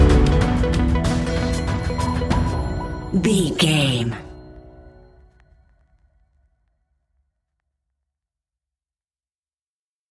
In-crescendo
Thriller
Aeolian/Minor
ominous
haunting
eerie
electronic music
Horror Pads
Horror Synths